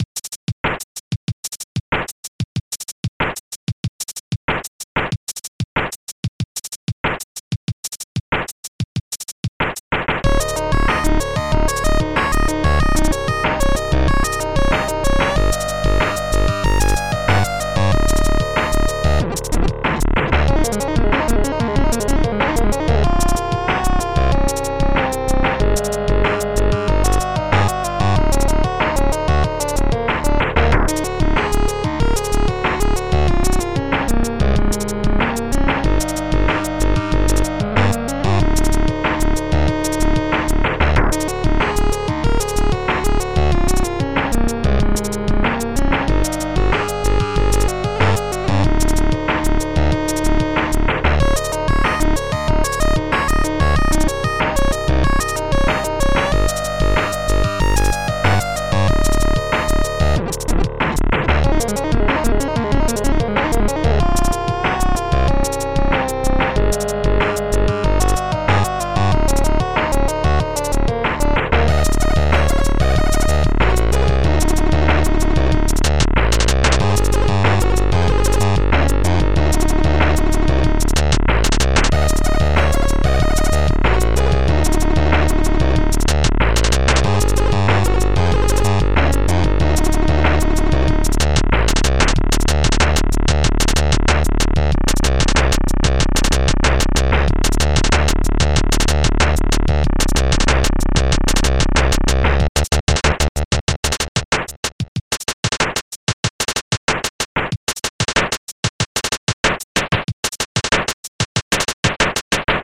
Instruments robhubbard-snare gbass1 back1 bassdrum1 hihat1 gbass2 lead1 lead2